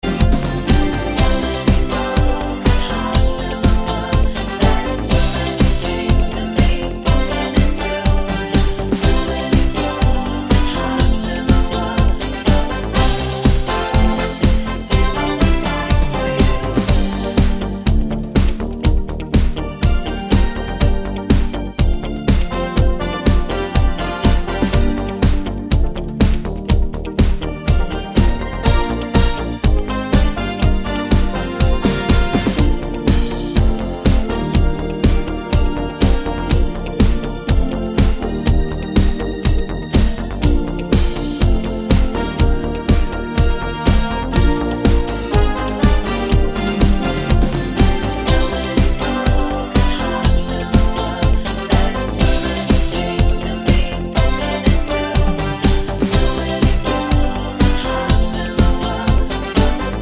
Key of A Major Pop (1980s) 3:16 Buy £1.50